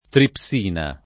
tripsina [ trip S& na ]